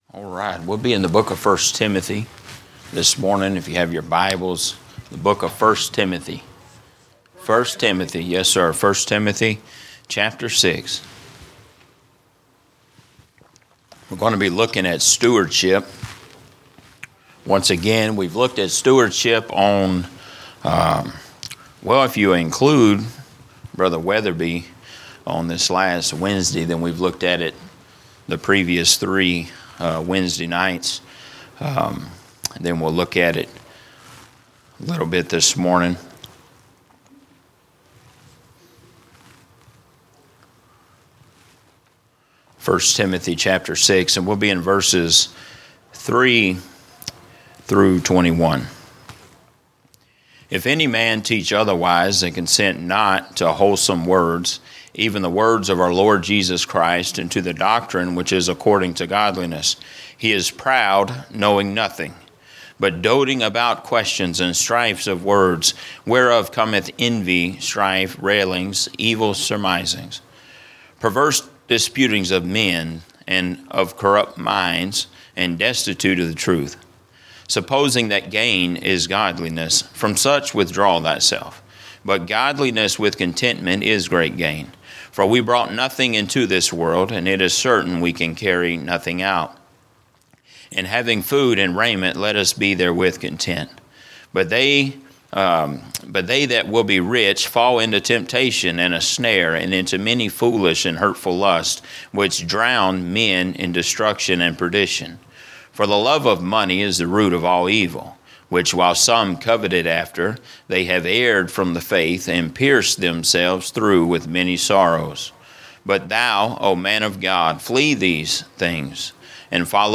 A message from the series "General Preaching." A look at stewardship